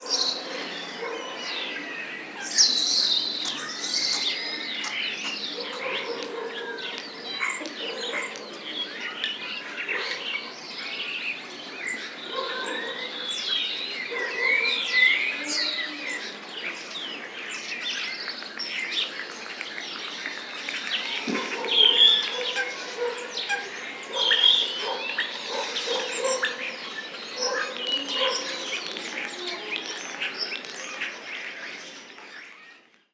Ambiente de campo con pájaros
pájaro
Sonidos: Animales
Sonidos: Rural